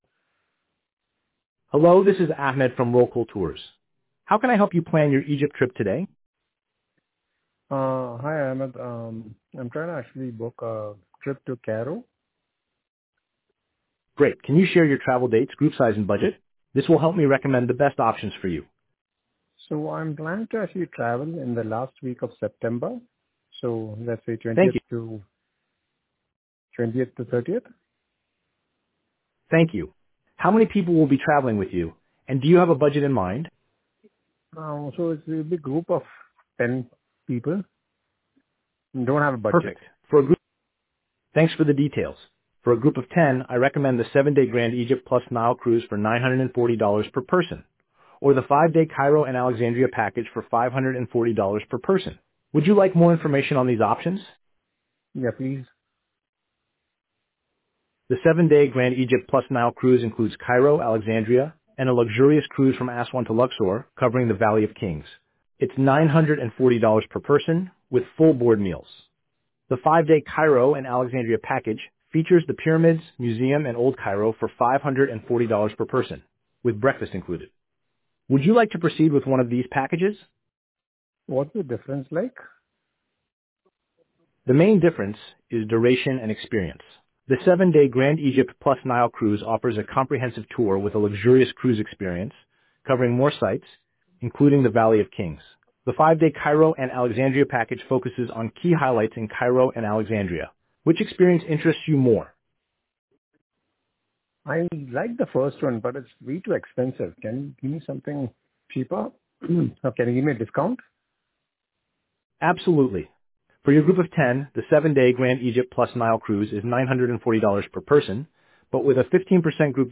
Tours-Sample-Call.mp3